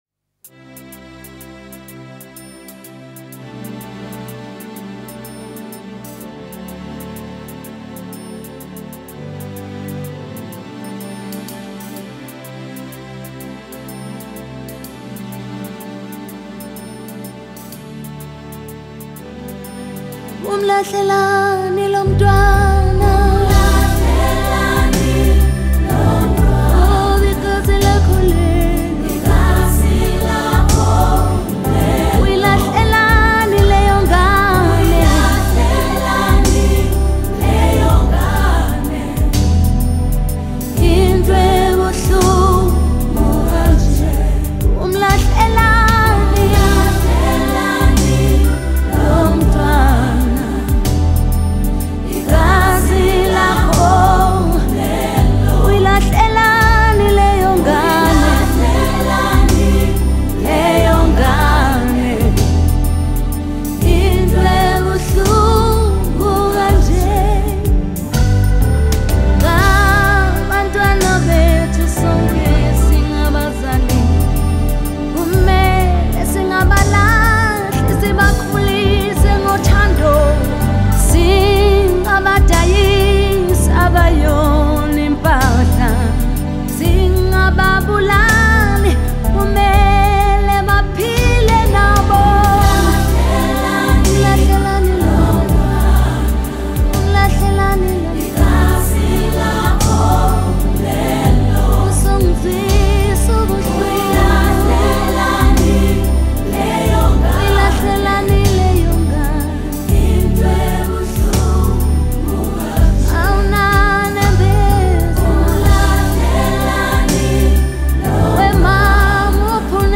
Gospel
was a South African gospel singer.